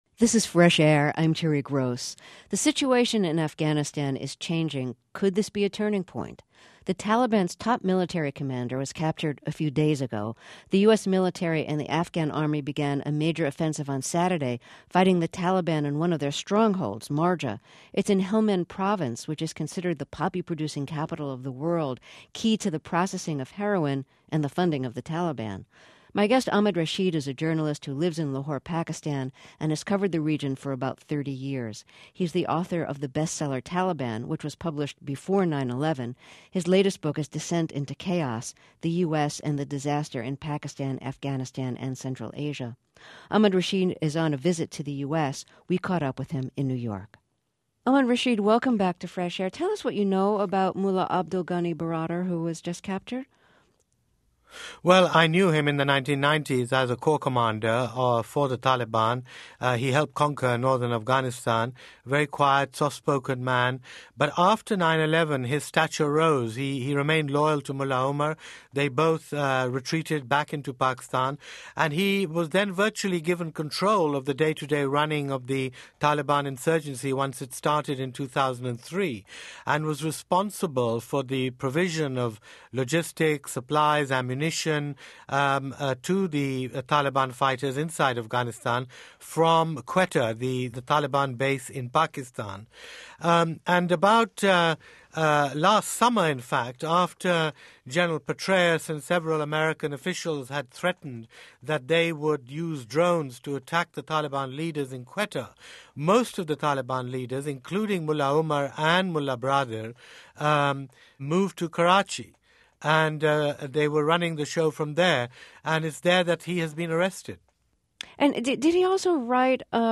NPR är amerikansk public service-radio.